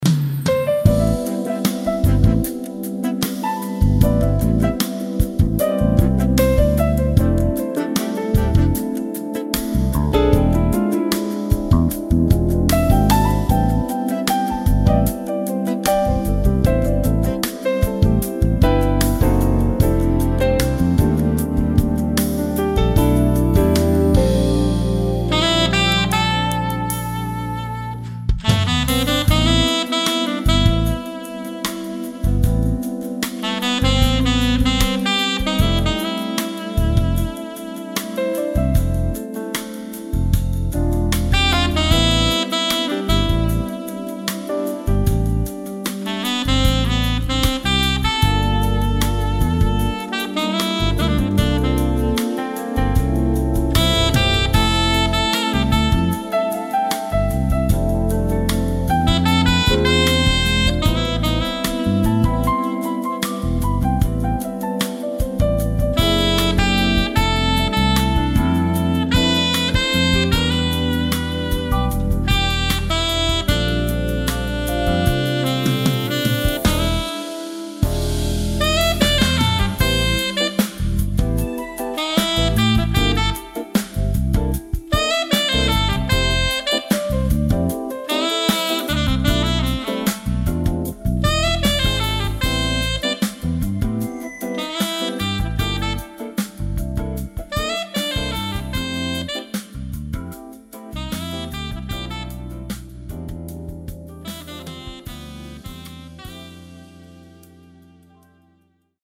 Genre: Smooth Jazz
For Radio Playlists: Smooth Jazz, Adult Contemporary